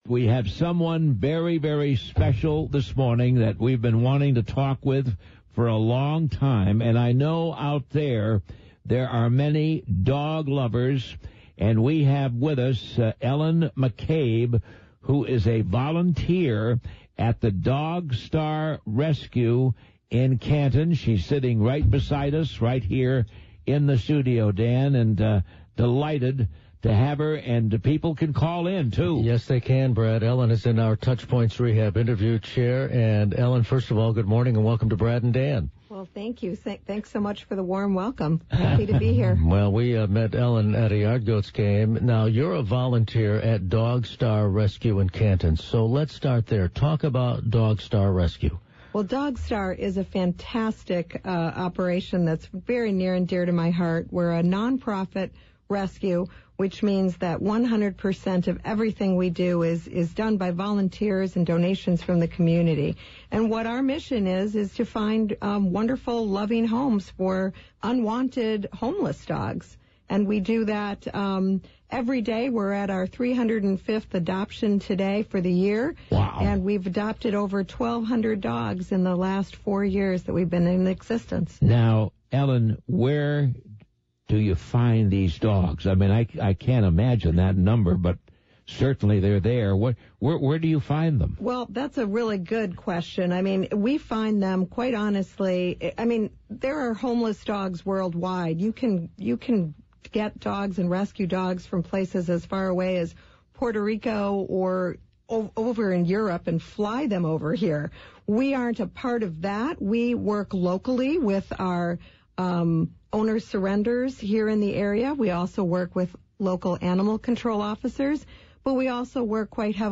In this interview, she talks about rescuing dogs, the volunteers who help to make it happen and how someone can adopt a dog.